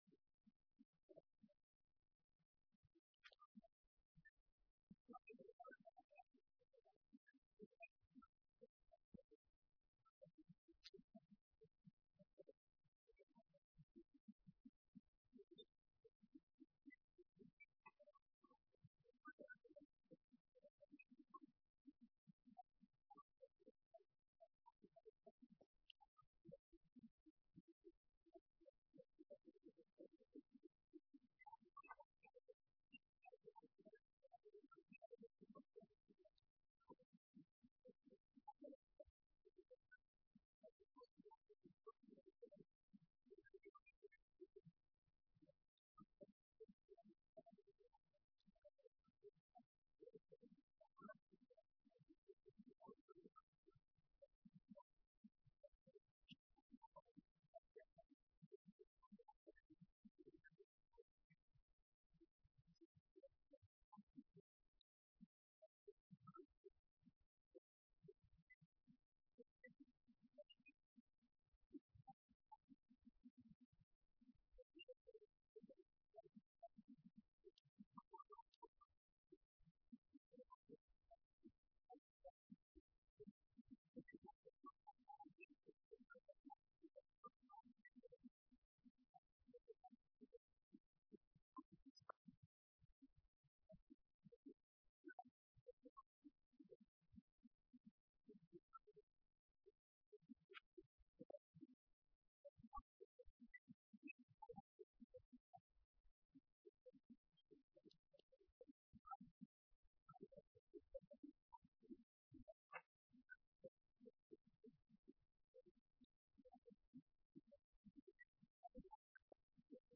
فاطمیه تهران
شب سوم محرم 96